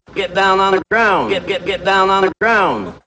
Hip-Hop Vocals Samples